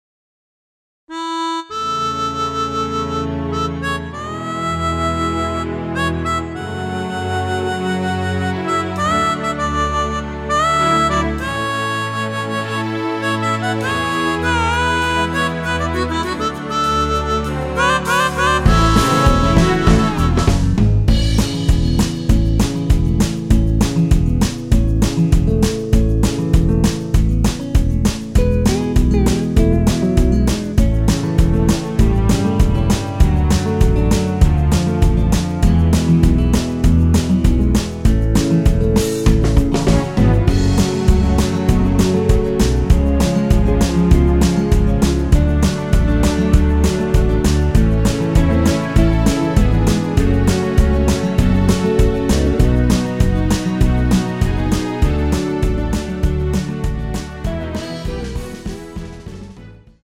전주가 길어서 8마디로 편곡 하였으며
원키에서(-3)내린 (1절+후렴)으로 진행되는MR입니다.
앞부분30초, 뒷부분30초씩 편집해서 올려 드리고 있습니다.